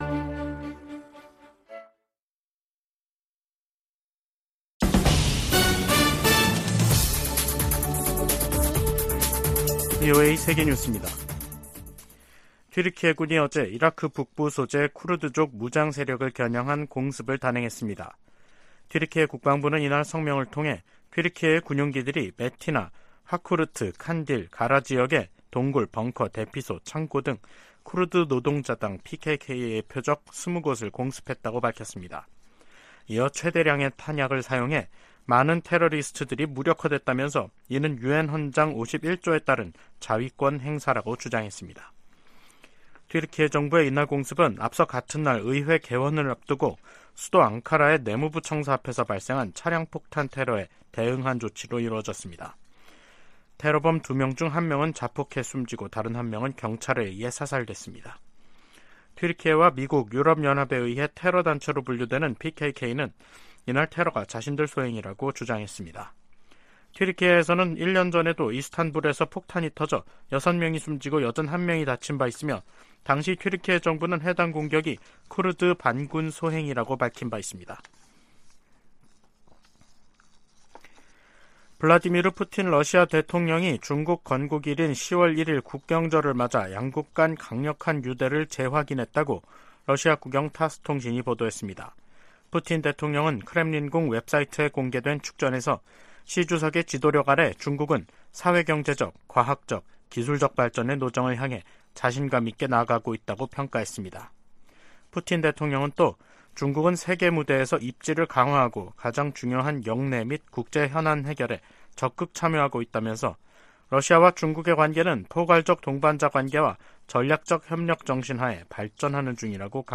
VOA 한국어 간판 뉴스 프로그램 '뉴스 투데이', 2023년 10월 2일 2부 방송입니다. 국제원자력기구(IAEA)는 오스트리아에서 열린 제67차 정기총회에서 북한의 지속적인 핵 개발을 규탄하고, 완전한 핵 폐기를 촉구하는 결의안을 채택했습니다. 북한이 핵 보유국 지위를 부정하는 국제사회 비난 담화를 잇달아 내놓고 있습니다.